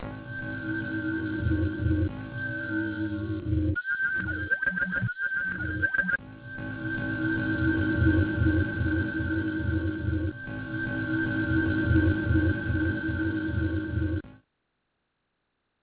Il ya huit samples, qui constituent la banque de sons avec laquelle j'ai composé les quatres exemples de mixage disponibles sur cette page .
Pour vous montrer toute la mobilité que m'offre l'improvisation en concert avec des bandes crées en studio, j'ai mixé pour le Terrier quatre versions possibles de cette banque de samples.